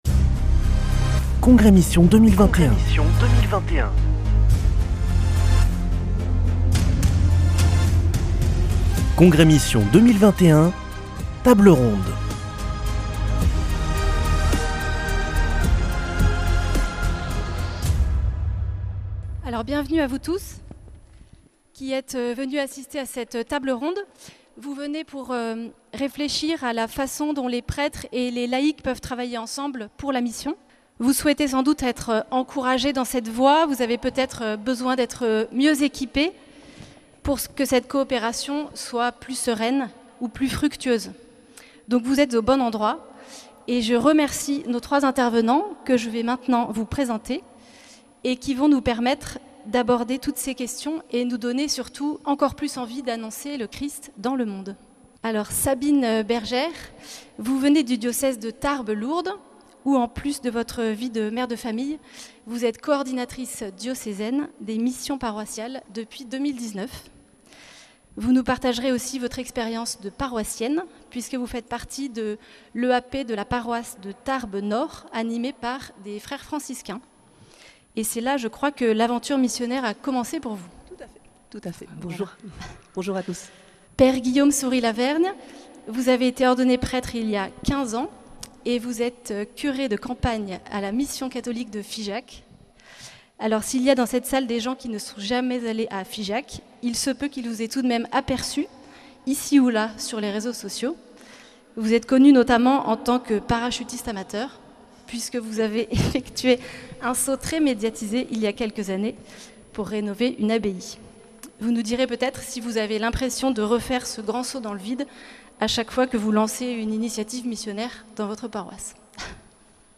Congrès Mission du 1er au 3 octobre à Toulouse - Table ronde 4